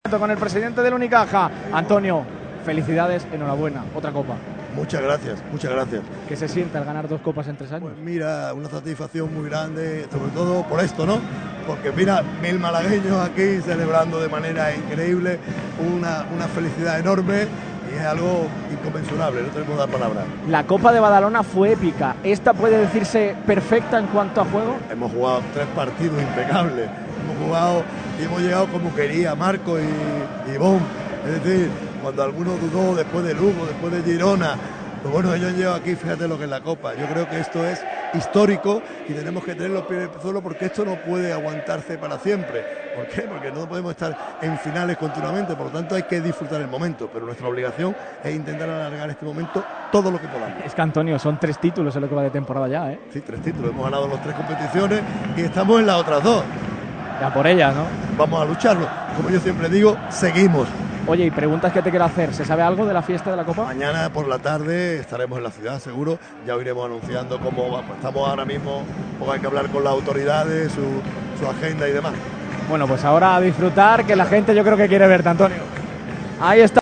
López Nieto habla en la radio del deporte | Radio MARCA Málaga
El presidente de Unicaja Baloncesto, Antonio Jesús López Nieto, y el alcalde de Málaga, Francisco De La Torre, han comparecido ante el micrófono de Radio MARCA Málaga en la celebración postpartido tars la consecución del cuadro de Los Guindos de su tercera Copa del Rey en Gran Canaria.